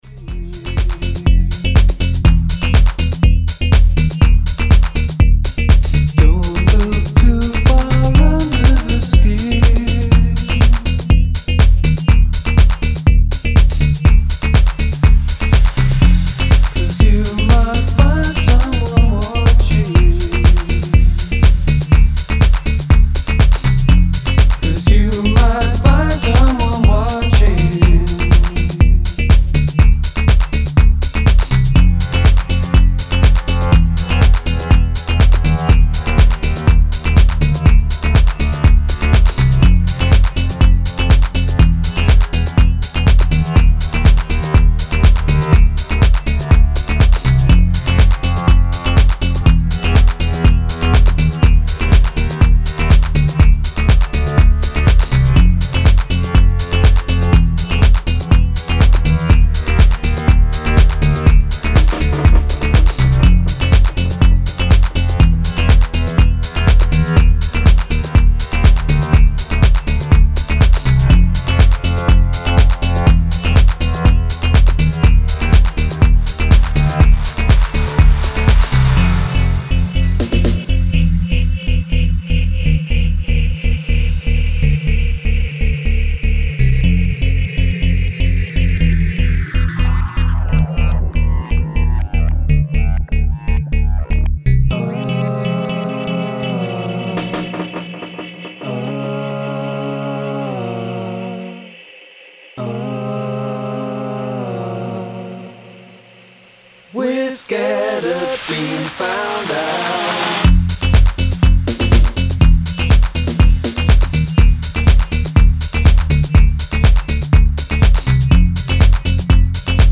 Style: House / Tech House